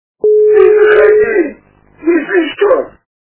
» Звуки » Из фильмов и телепередач » Падал прошлогодний снег - Ты заходи - если что...
При прослушивании Падал прошлогодний снег - Ты заходи - если что... качество понижено и присутствуют гудки.